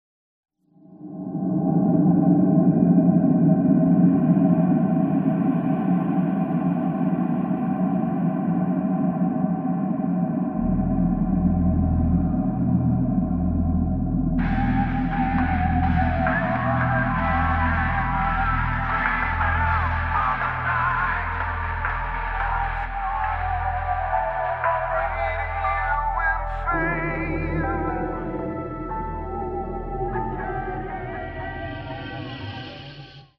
Category: Melodic Hard Rock
Vocals
Guitars
Drums
Bass
Keyboards